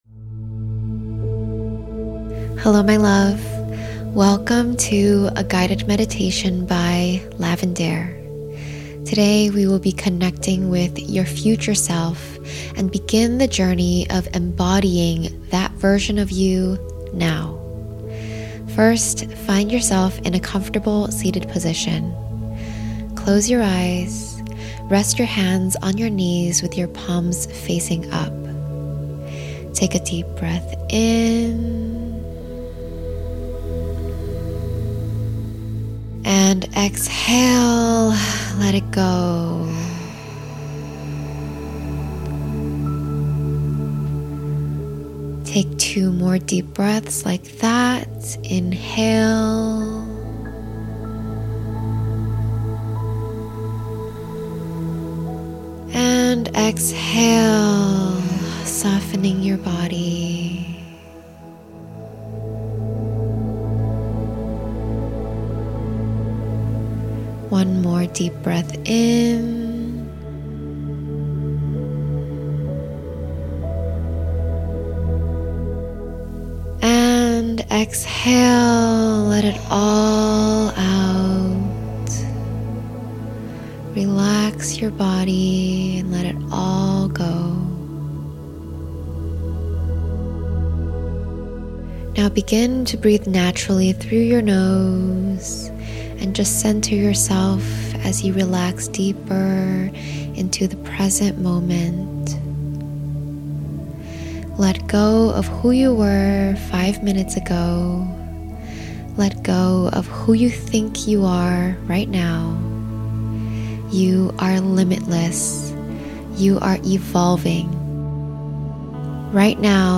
This guided Future Self meditation is designed to help you visualize your ideal life, connect with the next-level version of yourself, and begin embodying that energy today. You’ll be guided through visualization, identity design, mindset shifts, affirmations, and reflection to step into alignment with your goals and dreams.